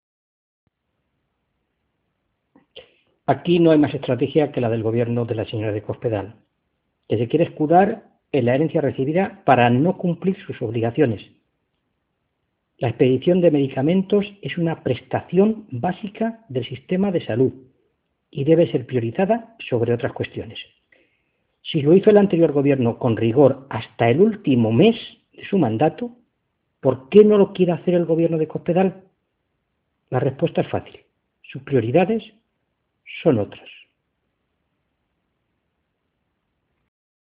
Fernando Mora, diputado regional del PSOE de C-LM
Cortes de audio de la rueda de prensa